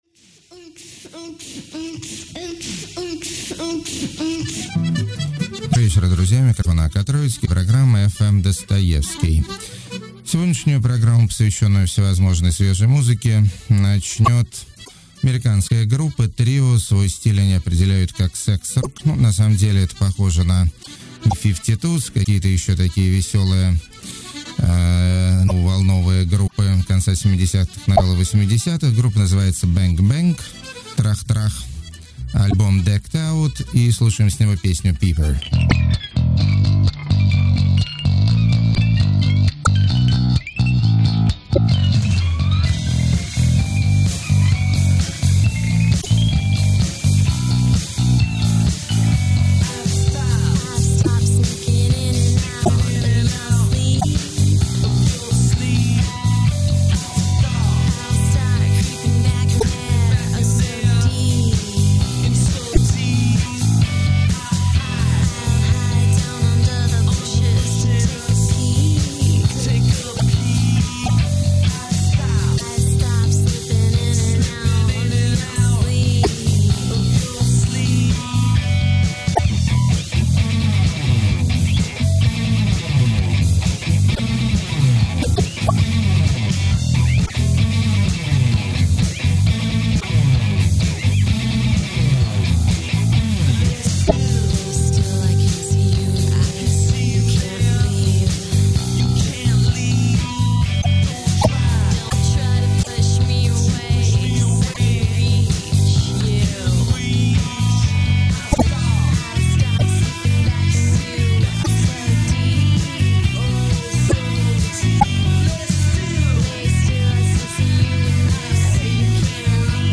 Запись с FM эфира, возможны помехи.